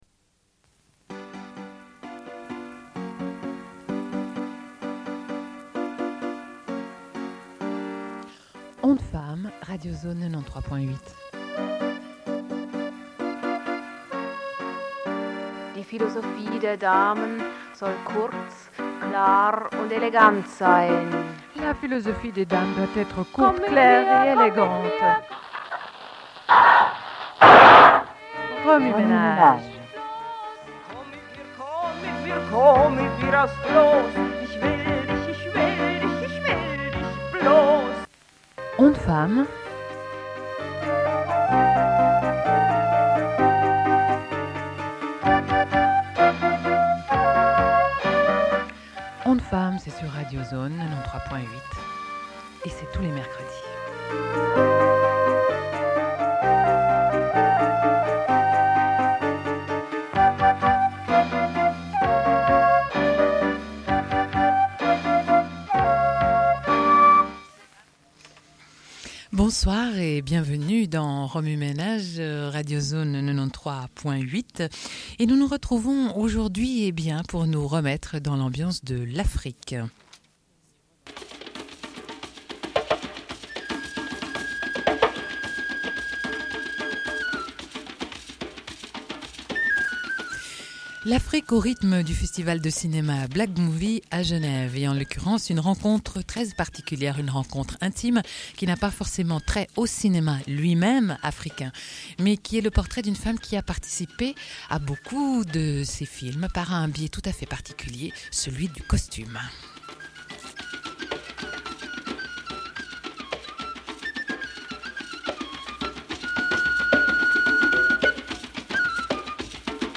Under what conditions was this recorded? Une cassette audio, face A31:26